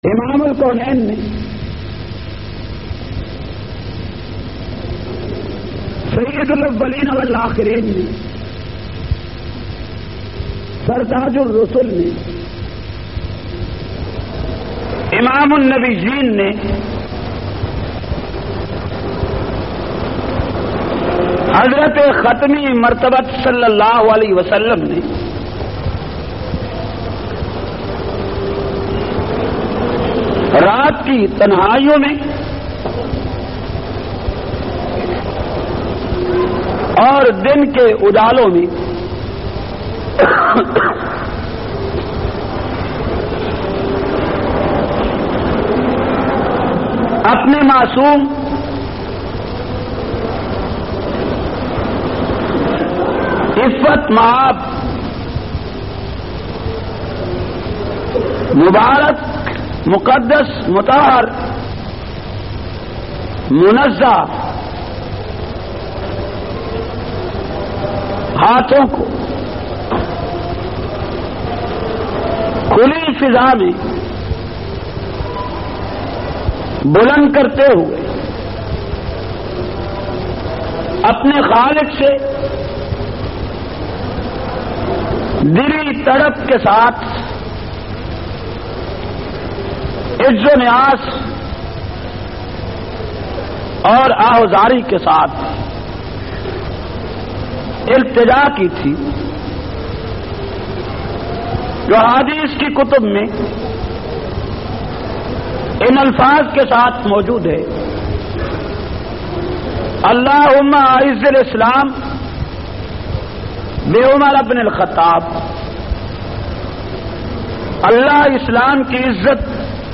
233- Shan e Farooq e Azam Jumma khutba Jhang.mp3